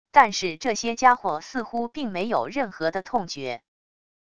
但是这些家伙似乎并没有任何的痛觉wav音频生成系统WAV Audio Player